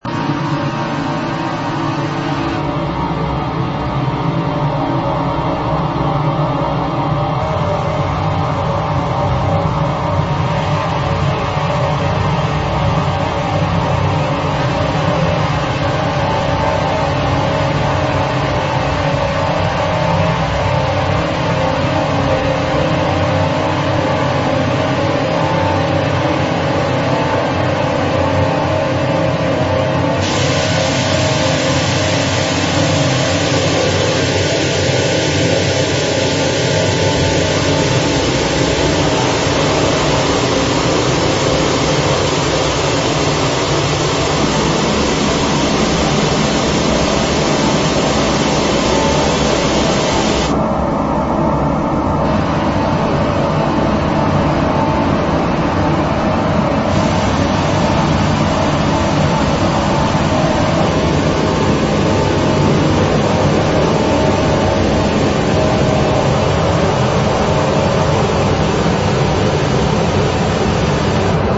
compilation of 30 Austrian composers on 4 CDs